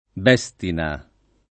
[ b $S tina ]